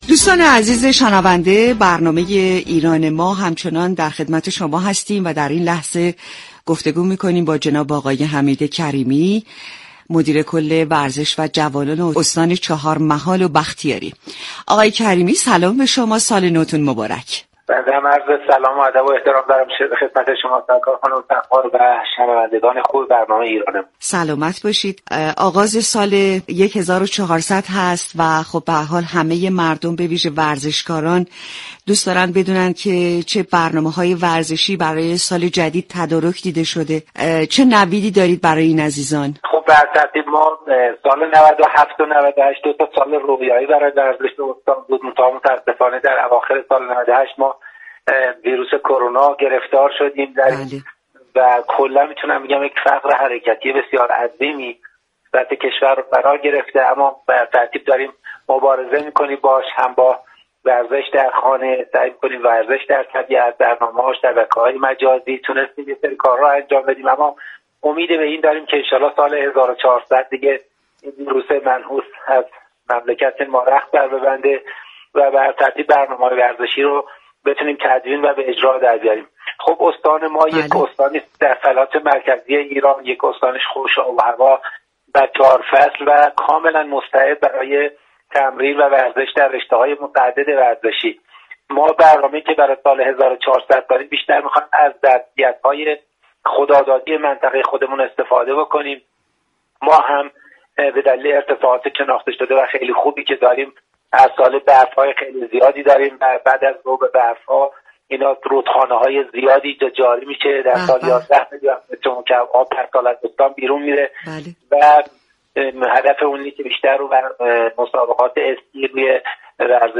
به گزارش رادیو ورزش؛ كریمی، مدیركل ورزش و جوانان استان چهارمحال و بختیاری در ارتباط با ویژه برنامه نوروزی ایران ما به گفتگو درباره ظرفیت های ورزشی استان استان چهارمحال و بختیاری پرداخت شما می توانید از طریق فایل صوتی پیوست شنونده این گفتگو باشید.